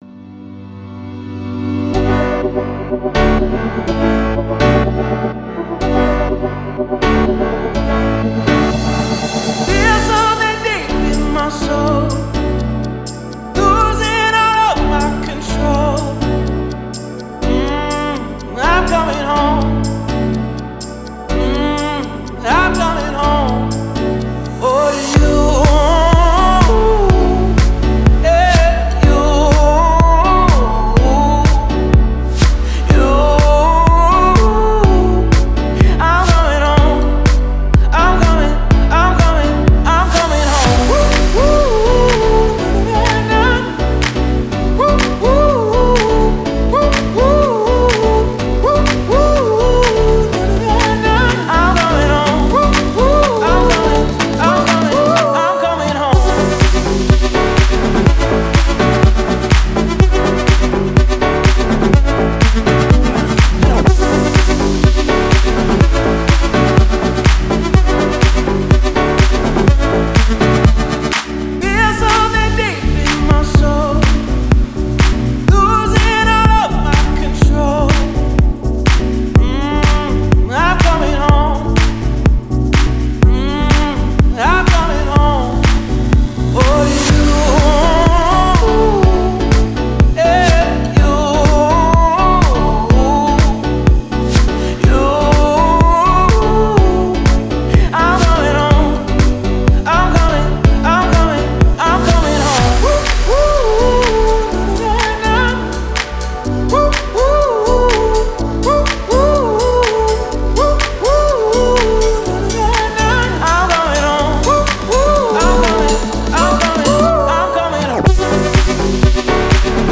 это трек в жанре EDM